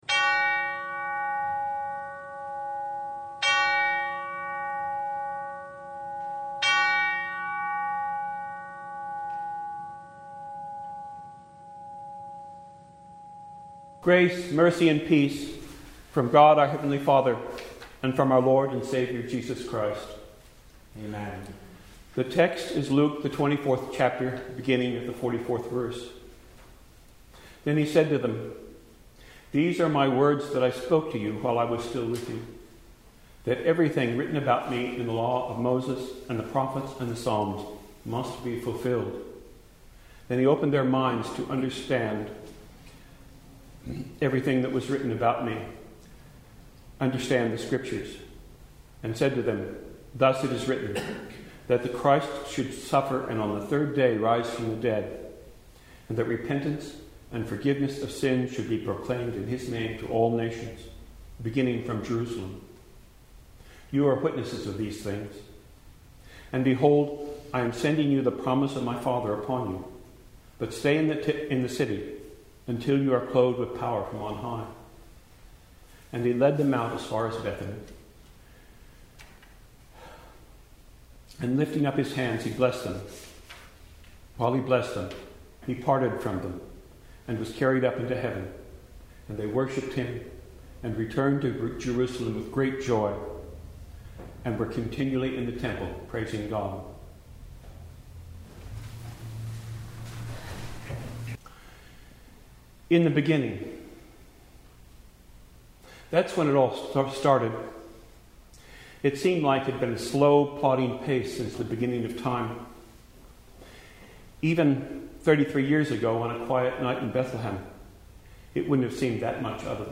The Ascension of Our Lord (Christ Lutheran Church, Troy, NH)
Service Type: The Feast of the Ascension of Our Lord